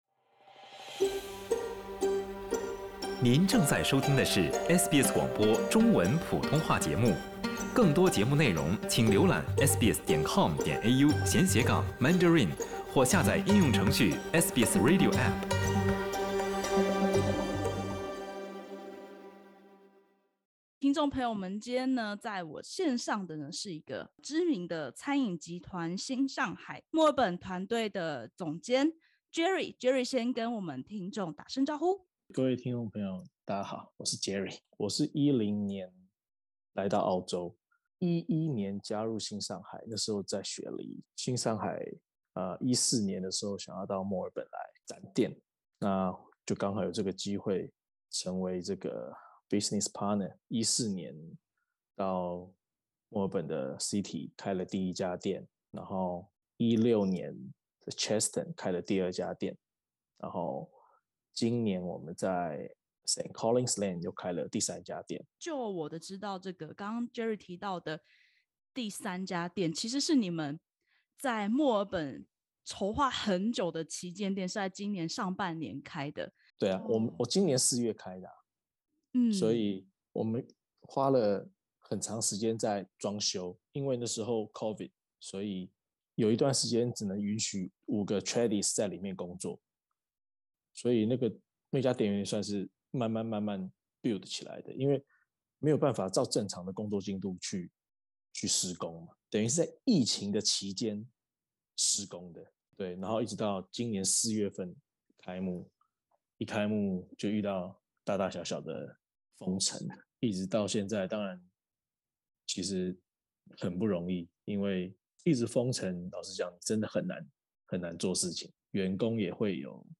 墨爾本正式解封近一個月，一家中式餐飲集團除了重新開放內用，還延續在封城期間的創新策略，立志服務更廣泛饕客。點擊首圖收聽採訪podcast。